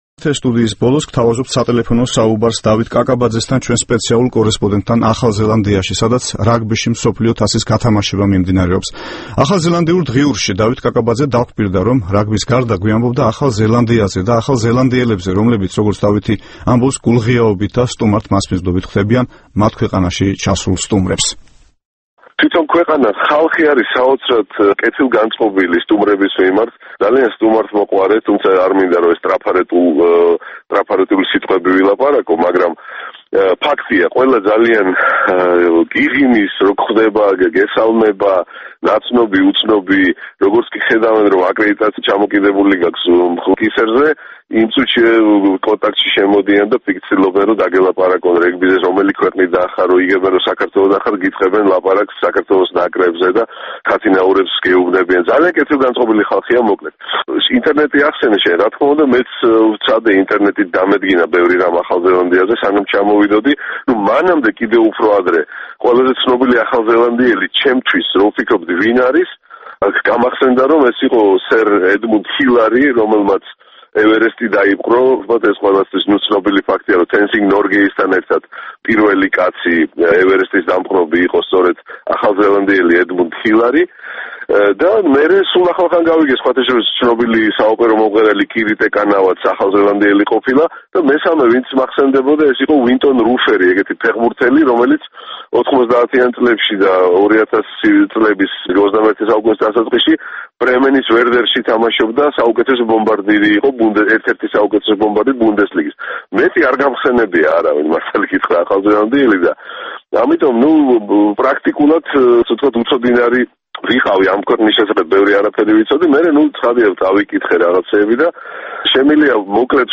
ახლა გთავაზობთ სატელეფონო საუბარს